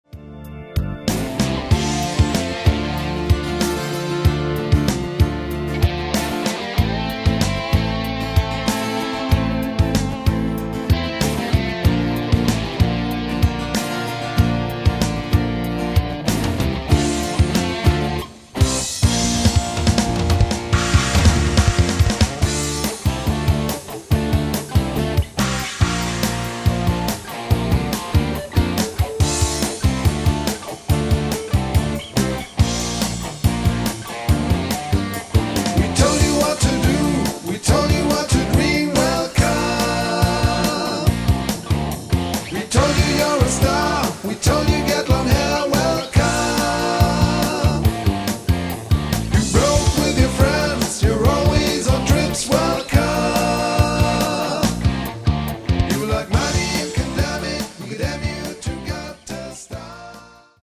ein reines Rock-Album